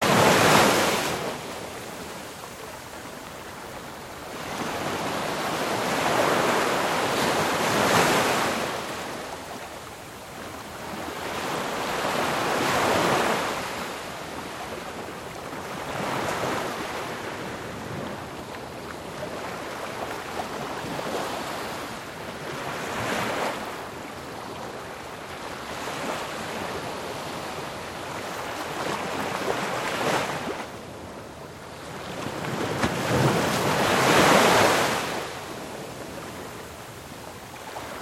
AmLife’s Original Frequency + Nature’s Frequency + Spiritual Healing Music
海浪滔滔 WAVES OCEANS 528Hz
稳定情绪 Stabilize the mood
wave.mp3